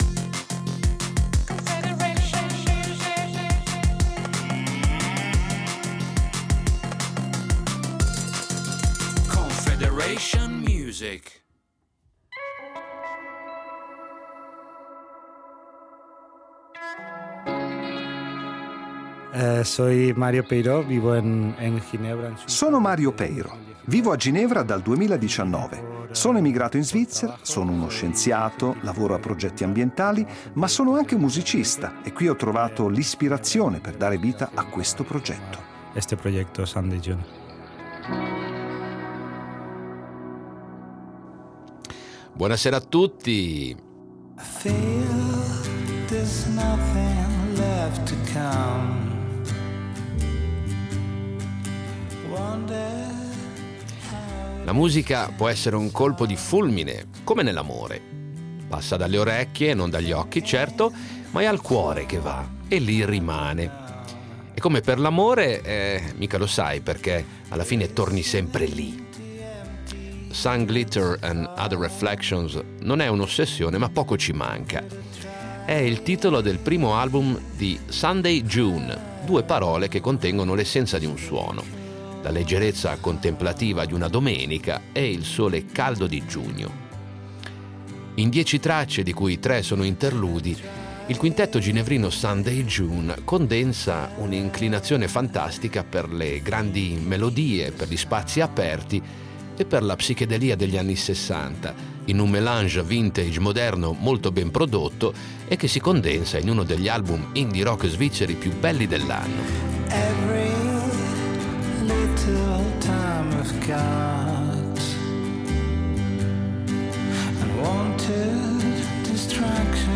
Musica rock Sunday